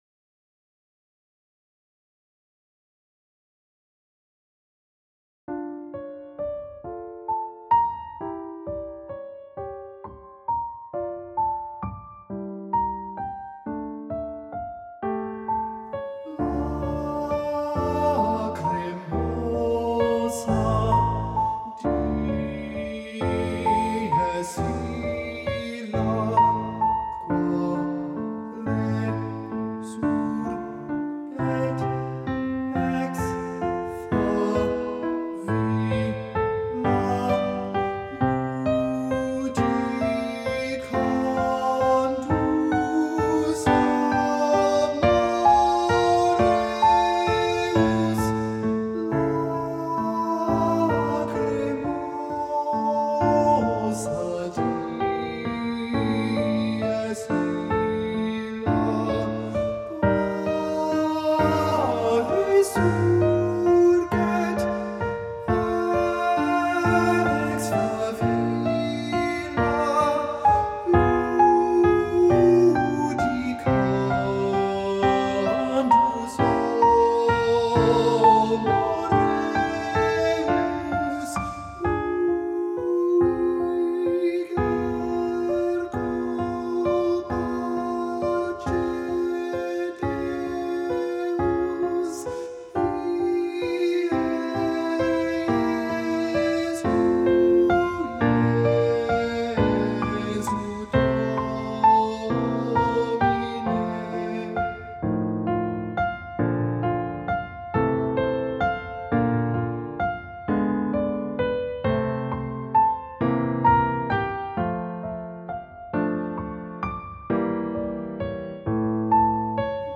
Attached are practice tracks for the Lacrimosa.